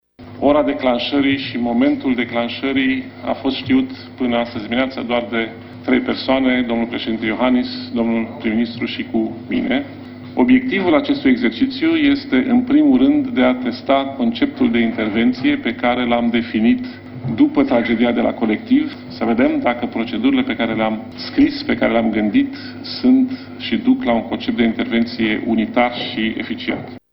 Ministrul Afacerilor Interne, Dragoş Tudorache, a anunţat că marţi dimineaţă, că a fost declanşat un exerciţiu ce simulează intervenţia autorităţilor în urma unui cutremur de peste 7 grade pe Richter, cu impact la Bucureşti şi Iaşi, scopul fiind verificarea timpilor de reacţie şi capacitatea de mobilizare: